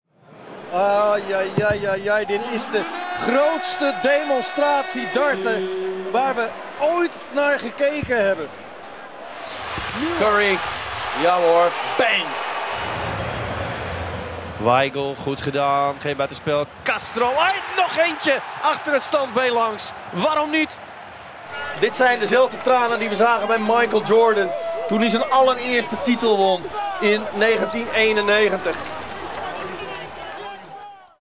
TV Shows
I have a friendly, knowledgeable, dynamic and/or emotional voice, but I can strike a lot of other tones as well.
- Soundproof home studio
Baritone